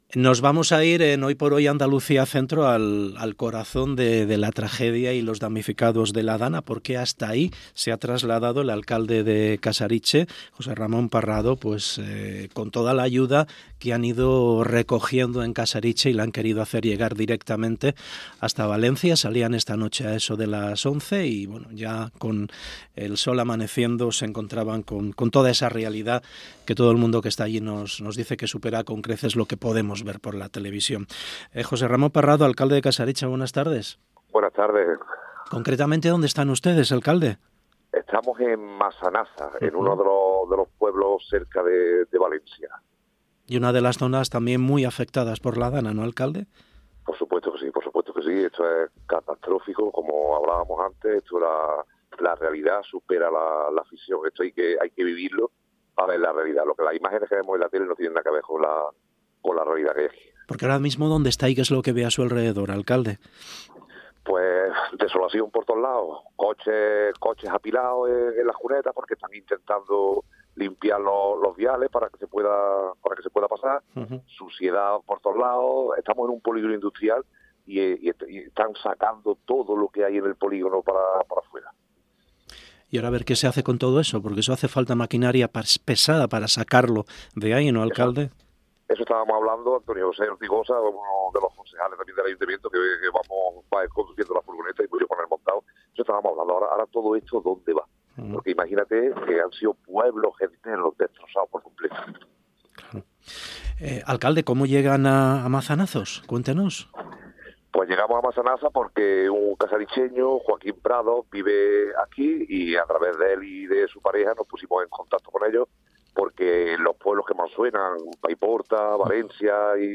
Entrevista Jose Ramon Parrado en Masanasa, Valencia